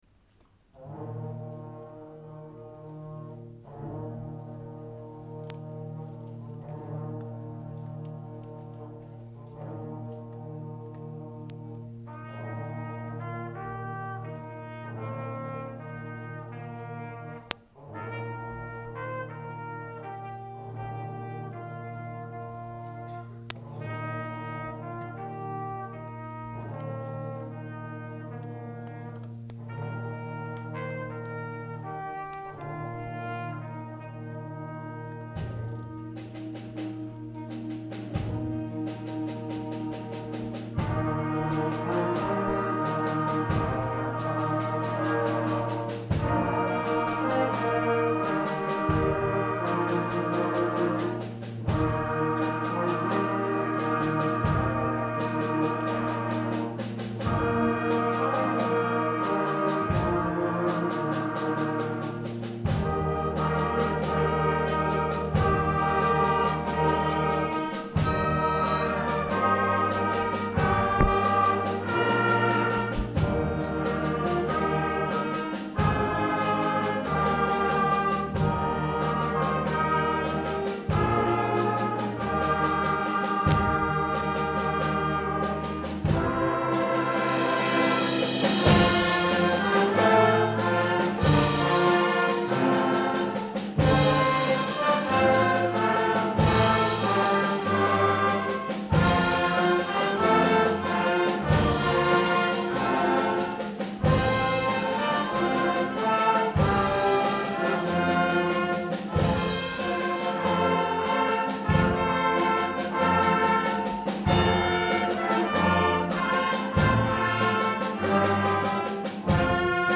junior wind band